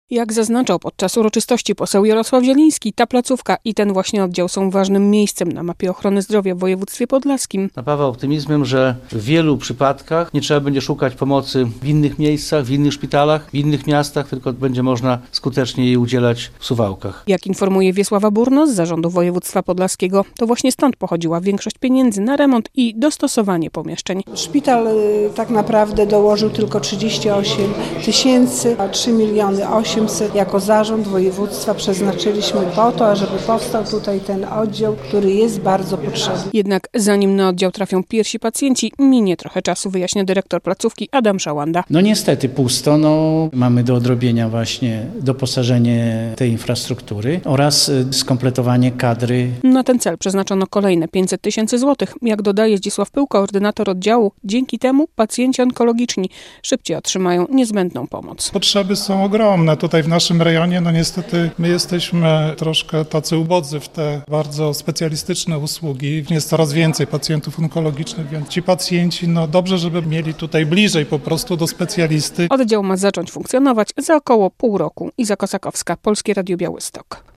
W suwalskim szpitalu na chirurgii ogólnej powstał pododdział onkologiczny - relacja